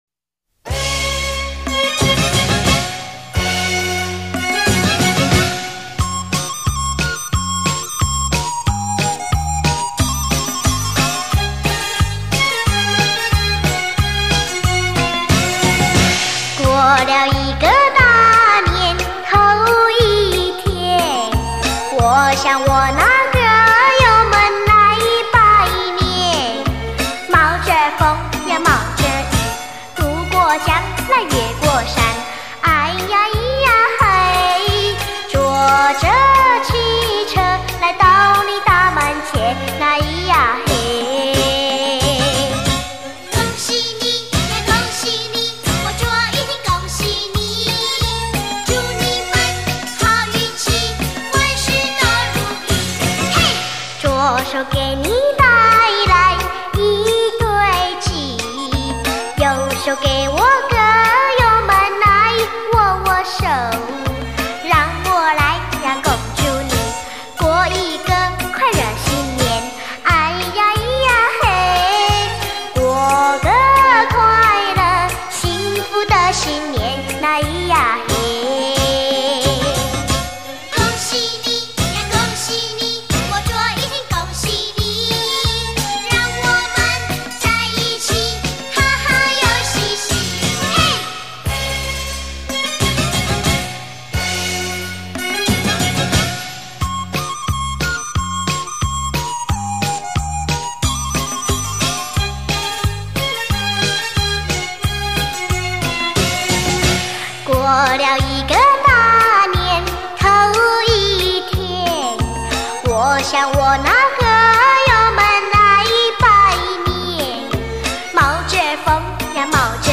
儿歌新年歌曲唱的真不错啊